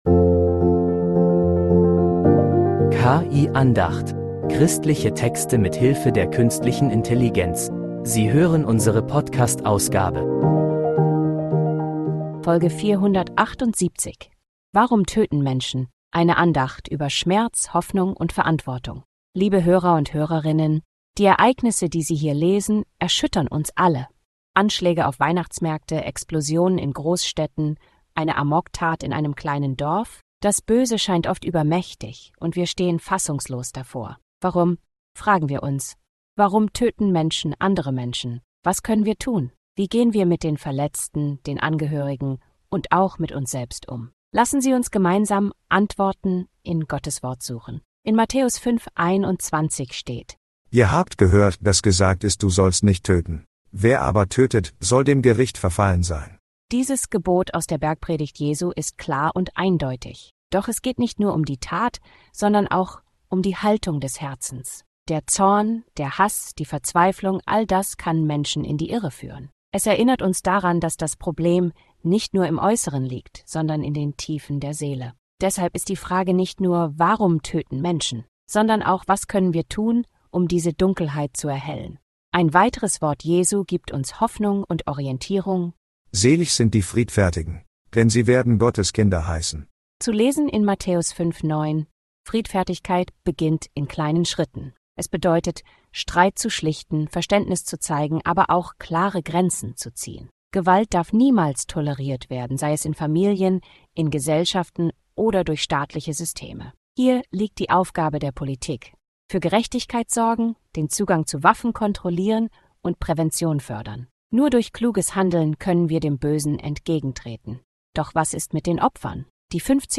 Eine Andacht über Schmerz, Hoffnung und Verantwortung